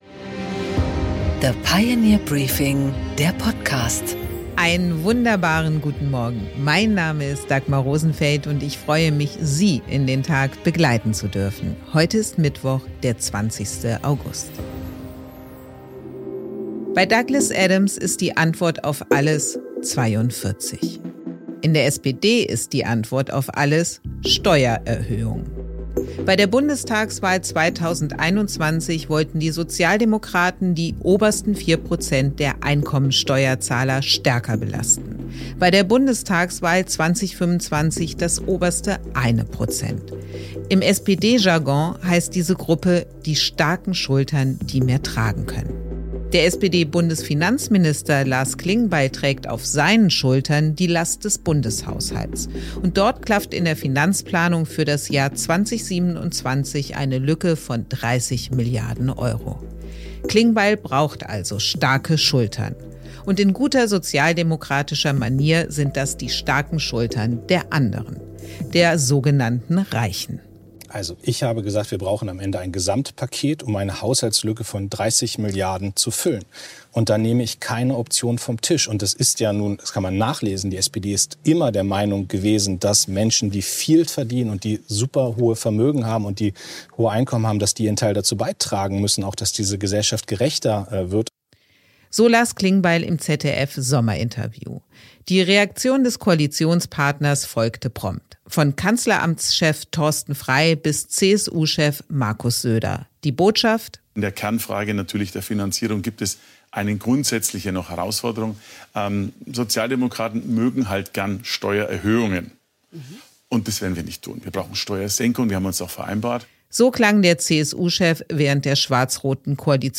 Dagmar Rosenfeld präsentiert das Pioneer Briefing